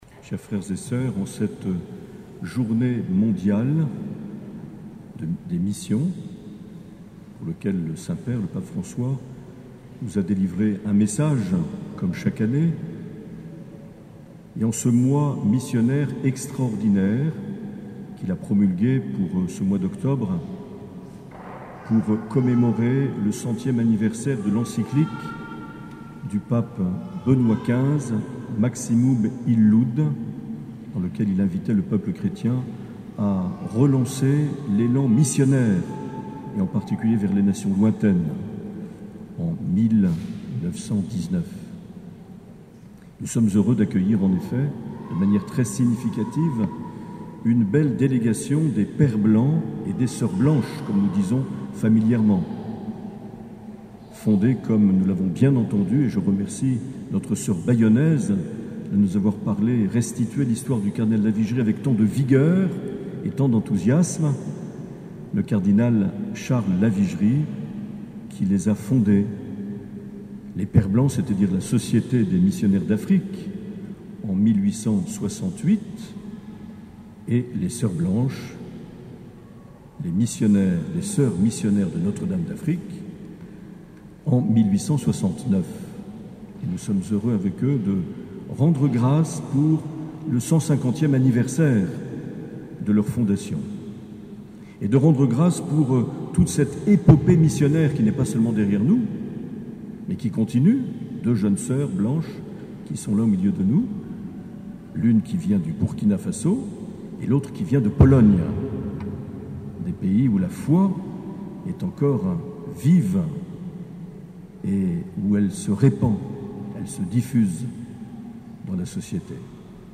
Les Homélies du 20 oct.
Une émission présentée par Monseigneur Marc Aillet